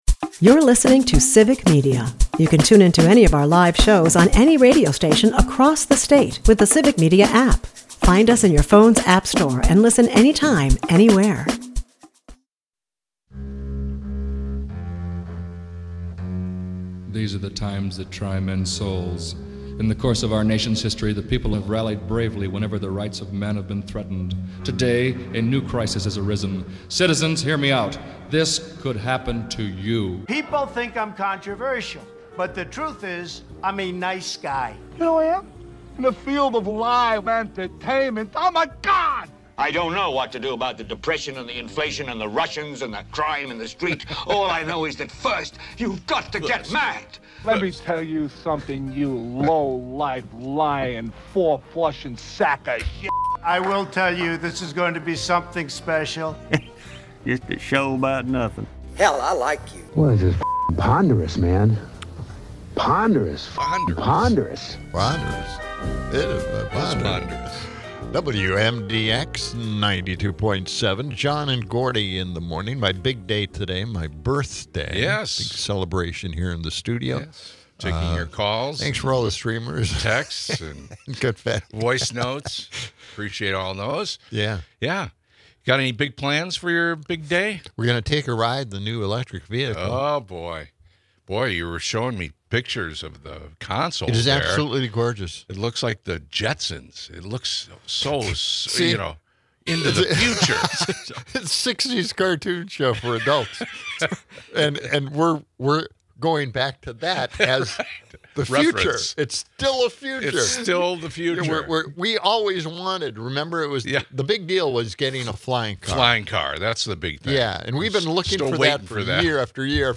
Charlie Berens humorously translates Midwest dialects in which the guys agree. The show features lighthearted exchanges about cereal preferences across generations and quirky Midwest phrases.